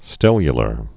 (stĕlyə-lər)